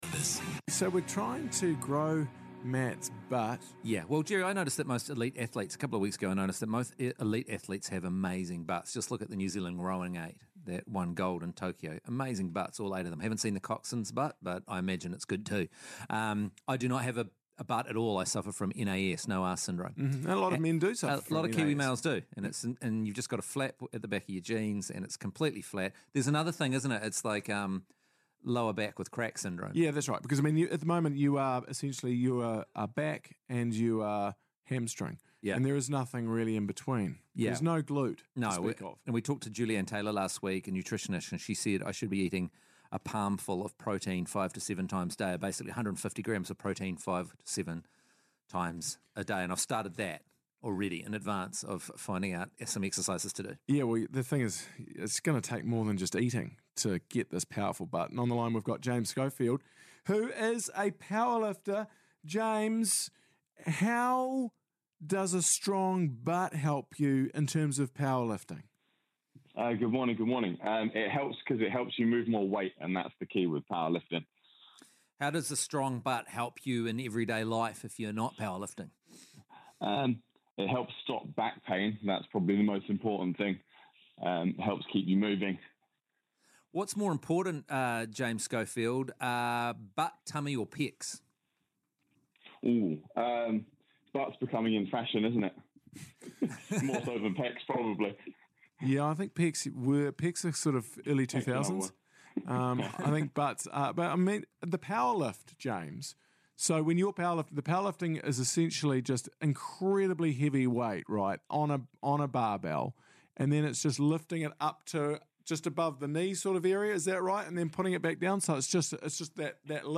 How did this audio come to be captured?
Here is the full interview clipped from the full show -